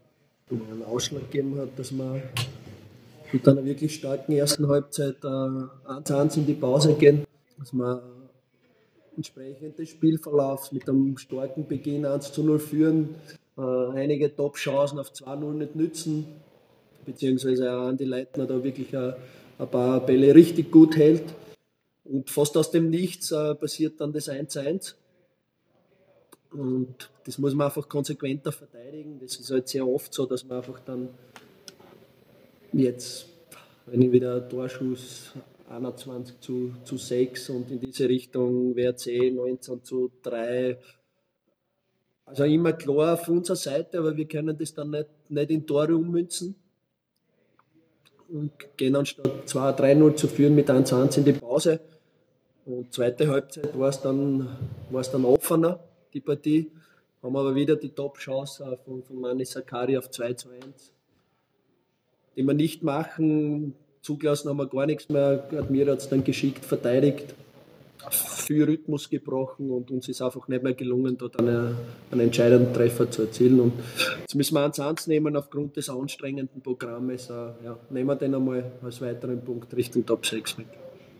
Die Spielanalyse des Sturm-Cheftrainers: